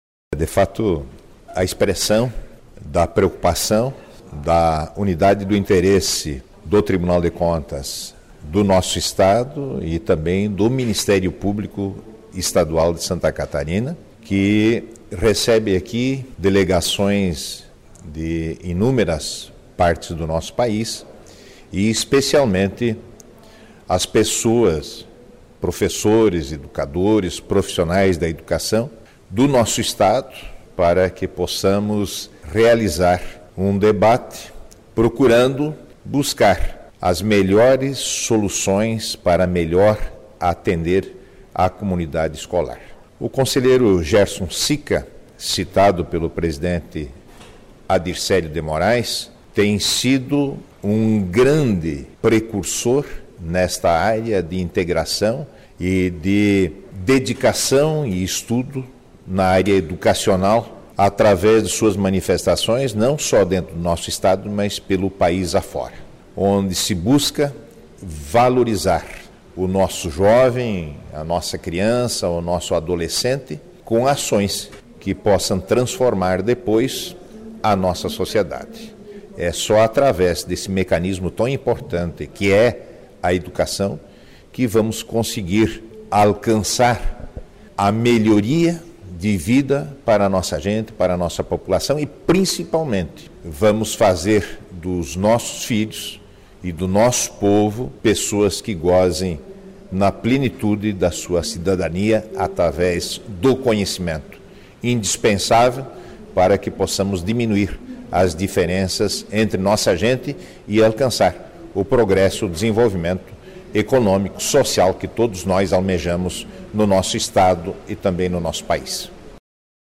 IV Sined e III Encontro de Promotores e Promotoras de Justiça da Educação - áudios dos participantes
Solenidade de abertura
Herneus De Nadal – conselheiro e vice-presidente do TCE/SC (